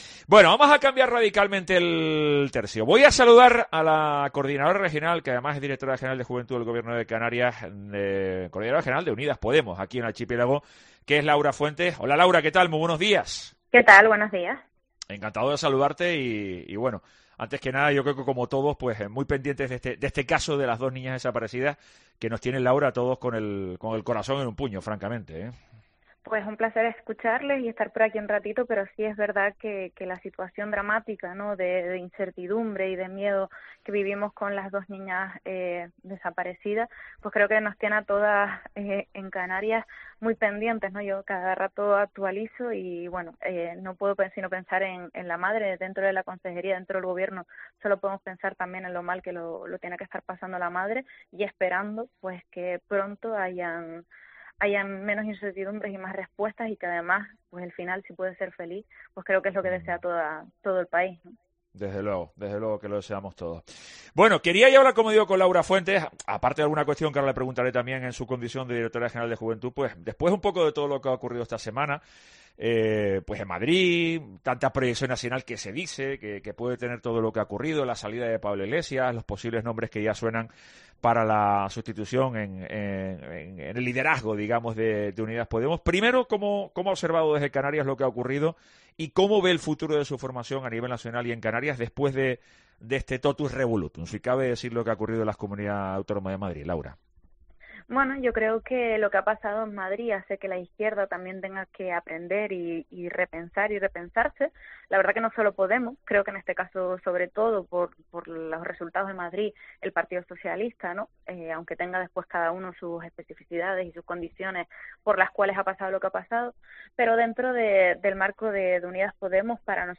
Laura Fuentes entrevista